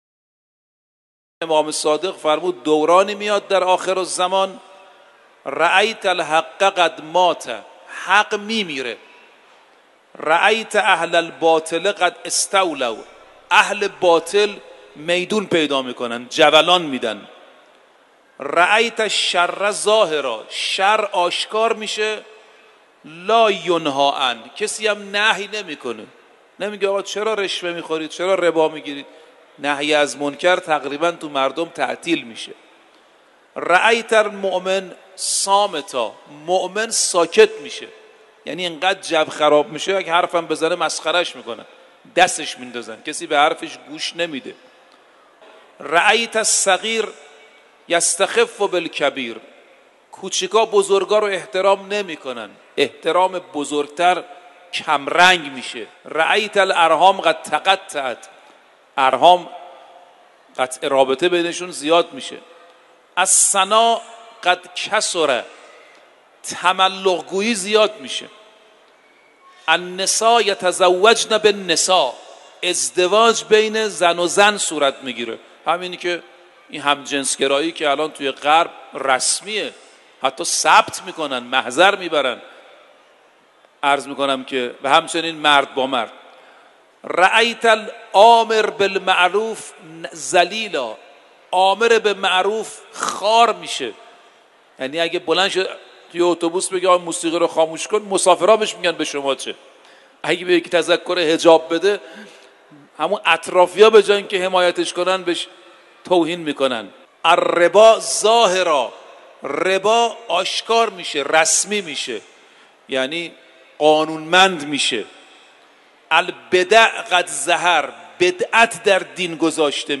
فرازی از سخنرانی
در حرم مطهر رضوی